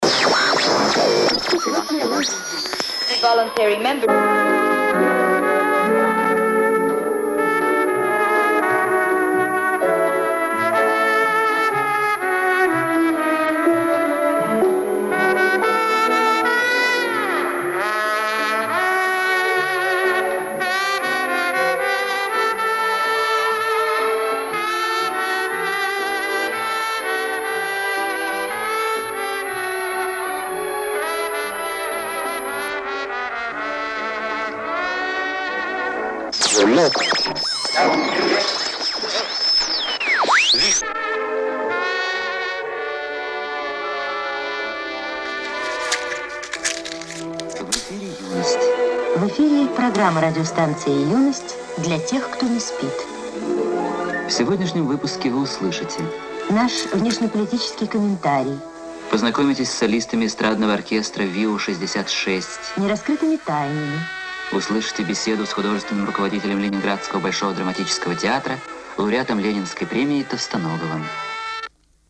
Здесь я даю фрагмент звуковой дорожки фильма